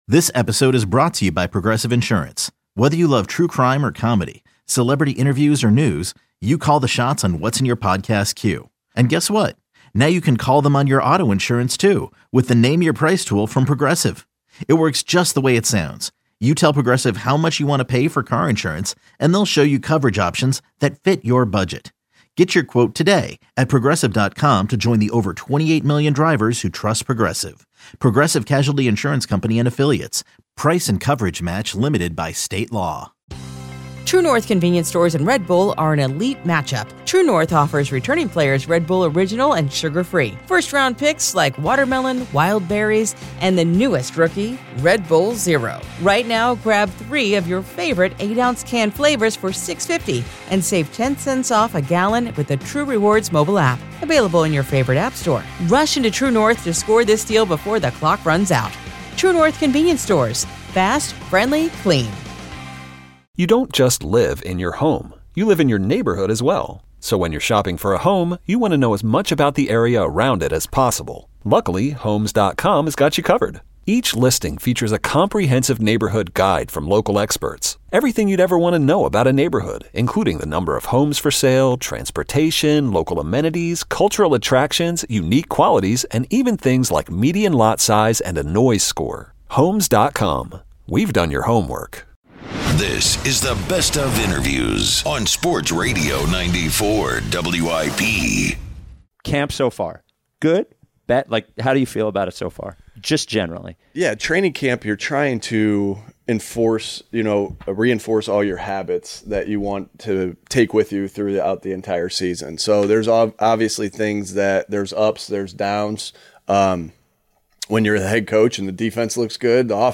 Best of Interviews on 94WIP: August 18-22
In the final week of Eagles Champ Camp on 94WIP, the WIP Afternoon Show sits down with Birds head coach Nick Sirianni as well as offensive linemen Lane Johnson and Cam Jurgens to discuss the upcoming season and much more.